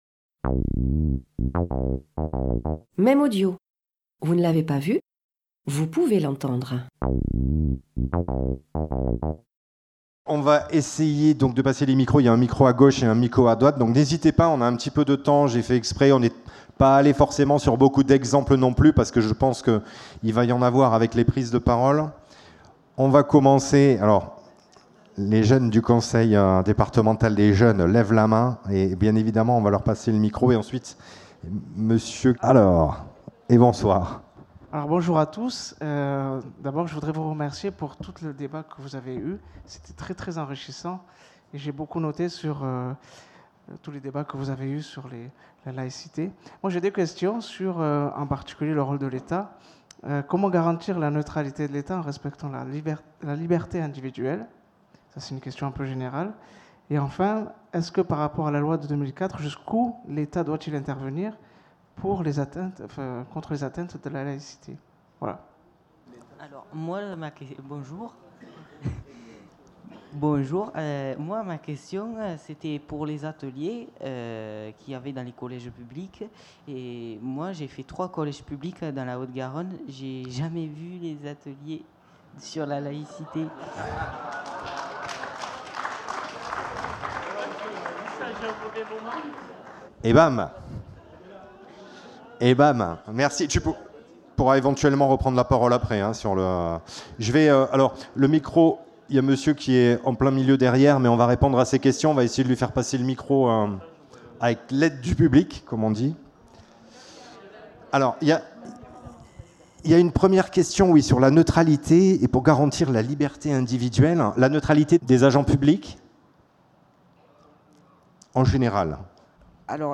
Table ronde
Dans cette seconde partie, vous pourrez entendre les échanges des intervenants.tes avec le public